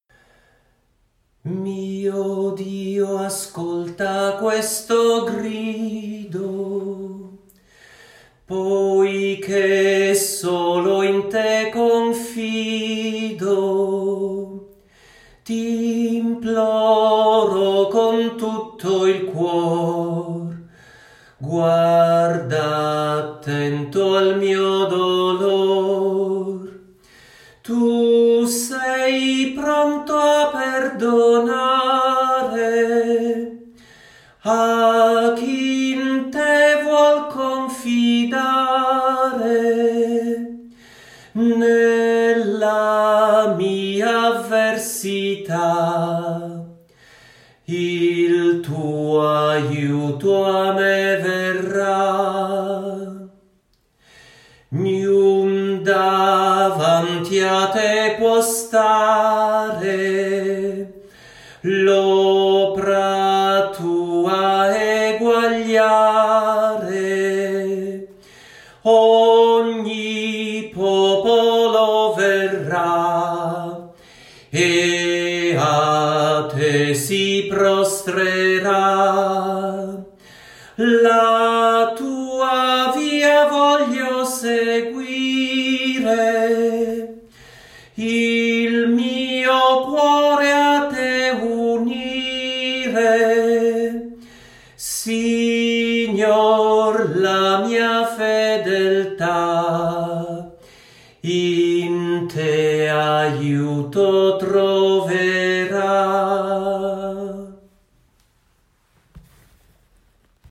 Salmi cantati
con accompagnamento della chitarra